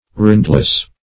Rindless \Rind"less\ (r[imac]nd"l[e^]s), a. Destitute of a rind.